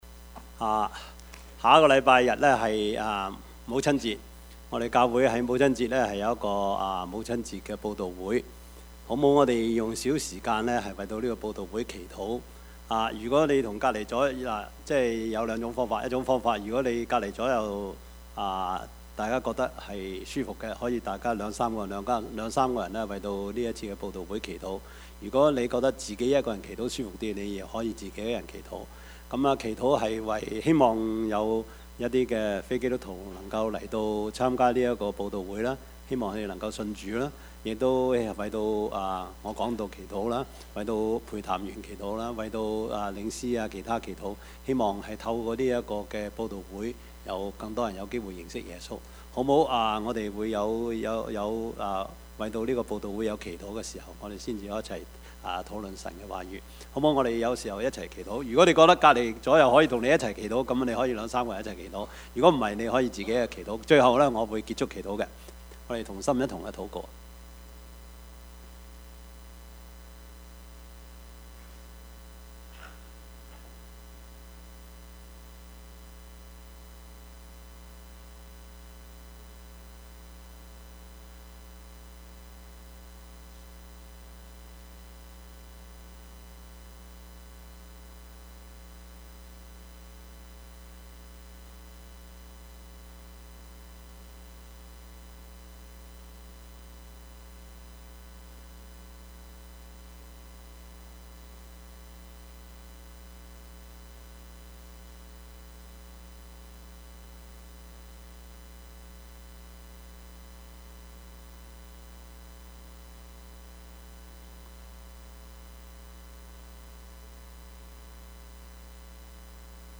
Passage: 路加福音十一：14-23 Service Type: 主日崇拜
Topics: 主日證道 « 魔鬼的愚民政策 阿媽唔易做 »